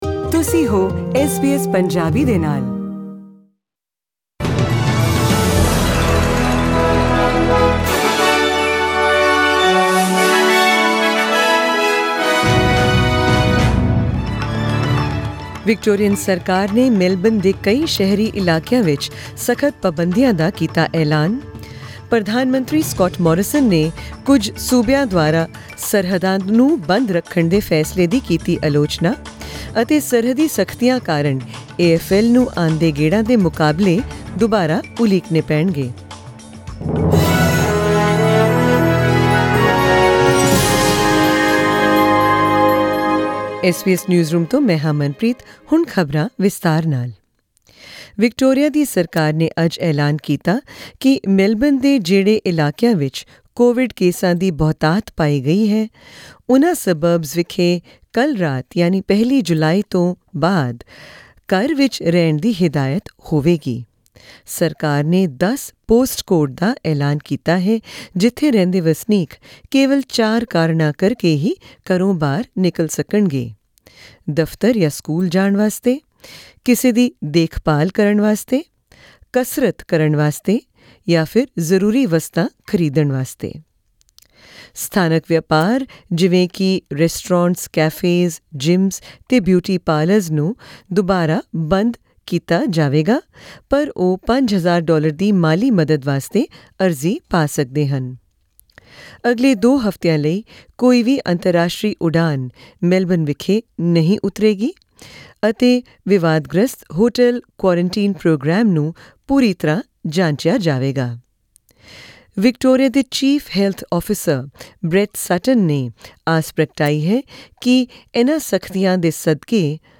In tonight's SBS Punjabi news bulletin: